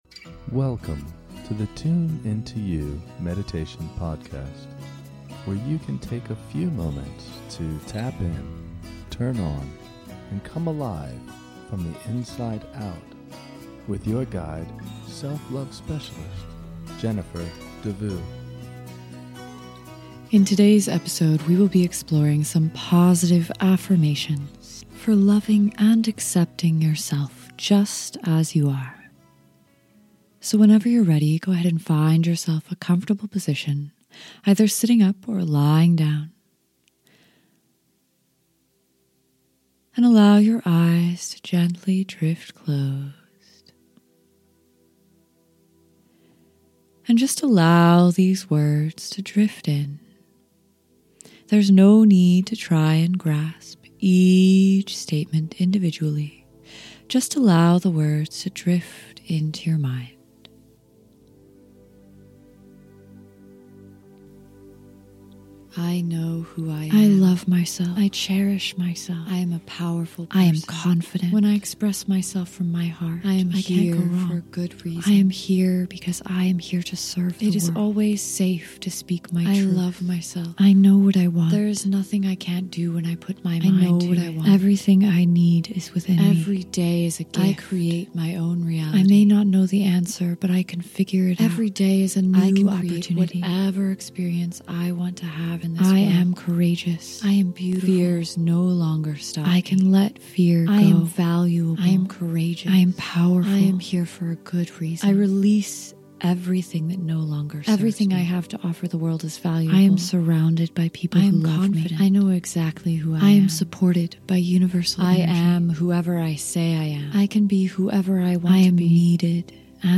This track includes a series of overlapping positive affirmations to support you in loving and accepting yourself just as you are.
Sit back, relax, and allow the soothing messages of this recording to enter your subconscious mind.